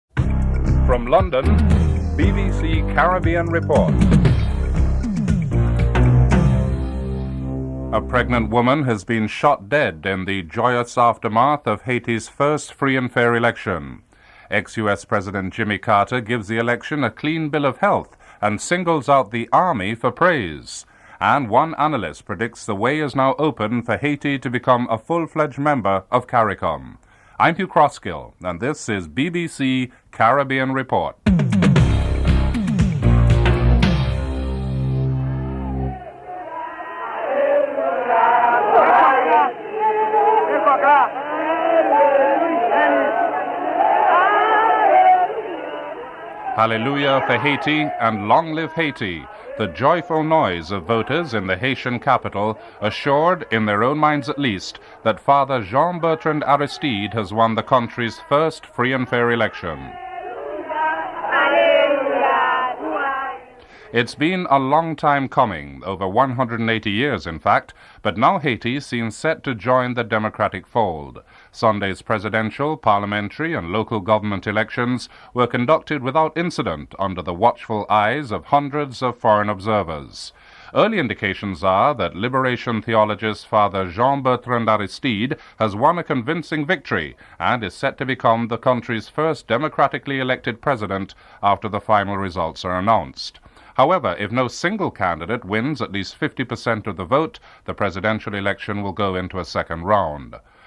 dc.contributor.authorCarter, Jimmy (speaker)